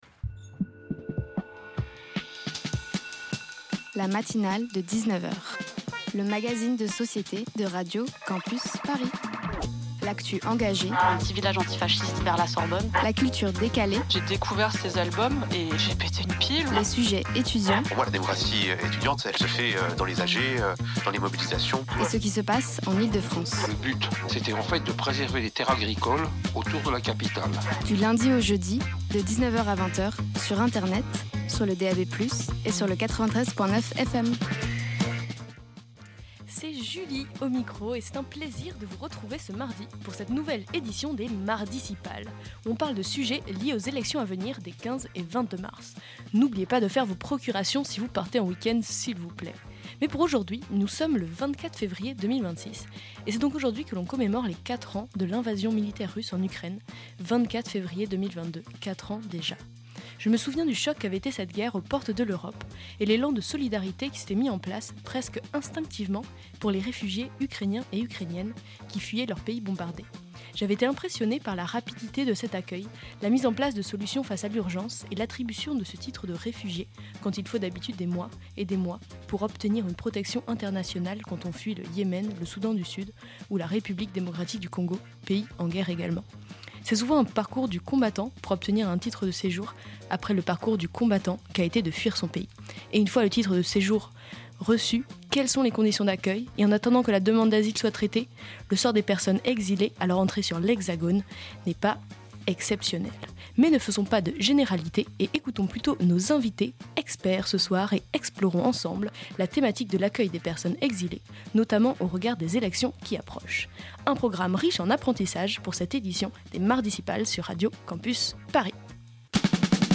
MARDICIPALES 2026 : Santé pour toutes et tous avec Le Revers & reportage à la Maison des Réfugiés